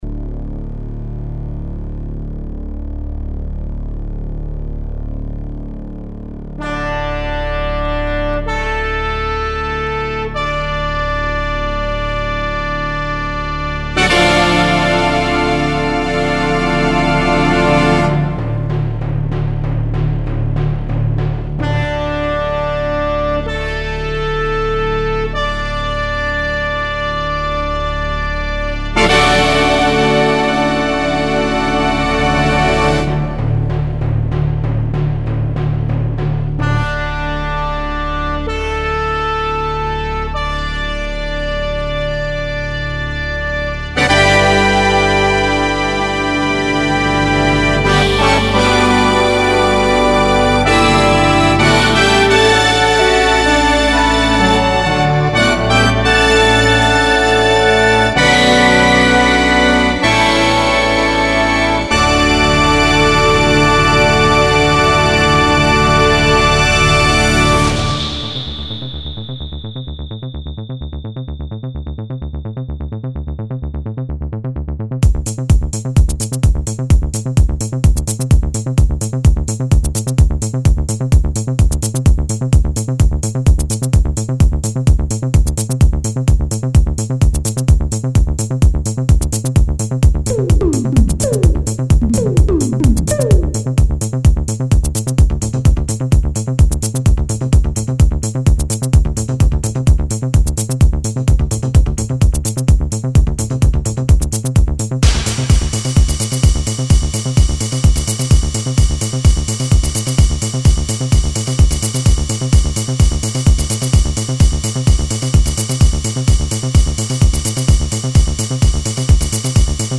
1 hour live set recorded
Disco , Electro , Tech-House 1 Comments